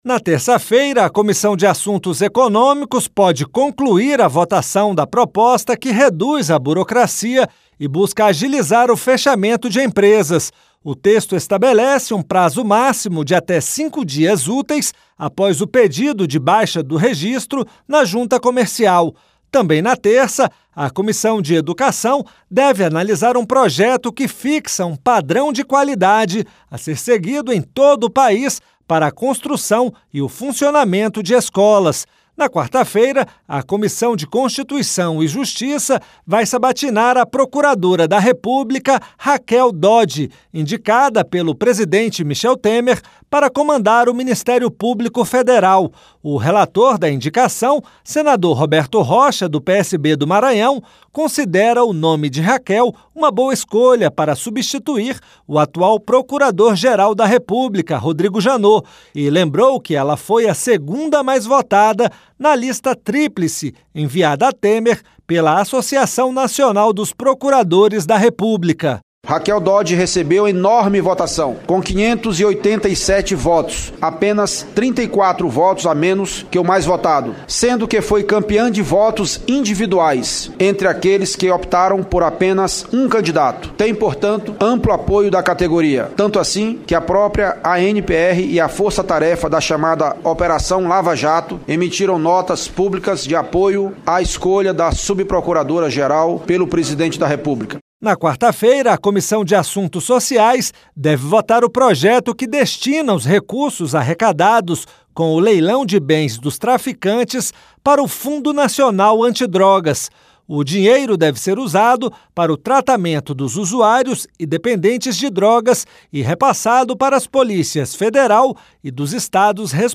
Confira alguns dos assuntos que serão tratados pelas comissões do Senado na próxima semana no áudio do repórter